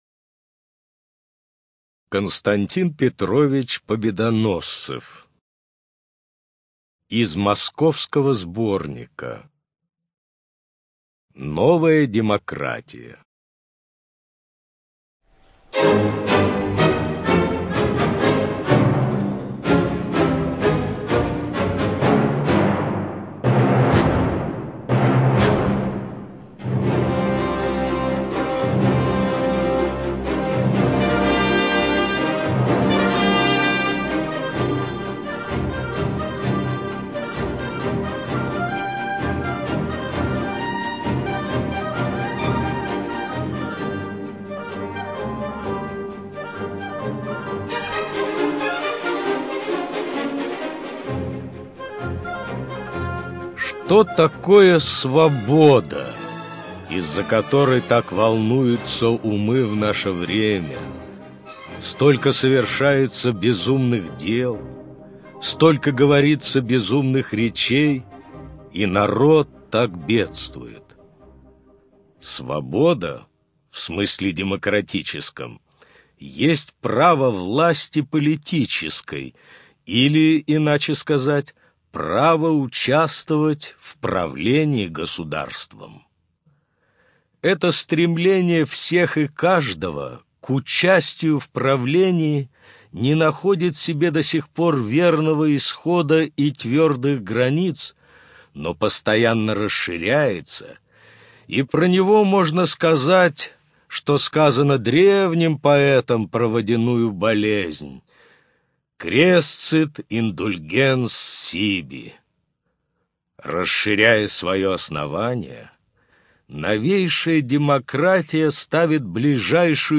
ЖанрИсторическая проза, Христианство